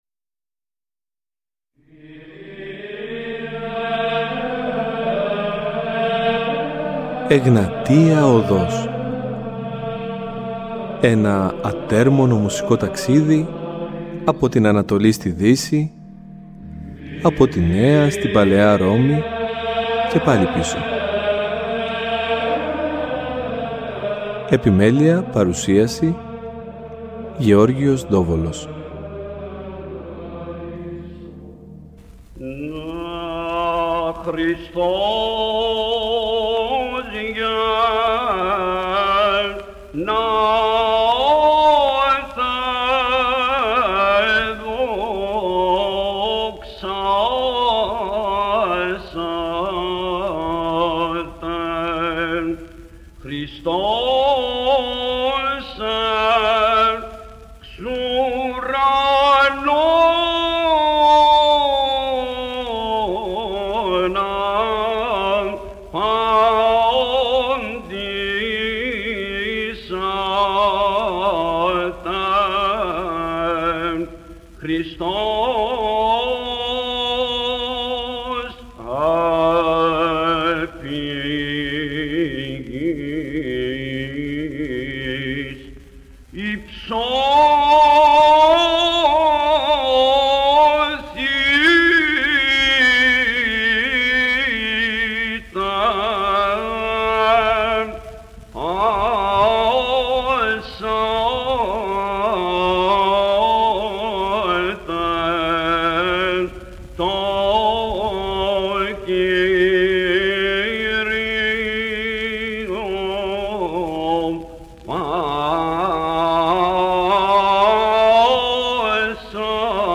Σπάνιες ηχογραφήσεις από την εποχή ήδη του γραμμοφώνου μέχρι και σήμερα που μας μεταφέρουν με μοναδικό τρόπο το κλίμα της μεγάλης Δεσποτικής εορτής.
Ύμνοι των Χριστουγέννων
Βυζαντινη Μουσικη